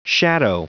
Prononciation du mot shadow en anglais (fichier audio)
Prononciation du mot : shadow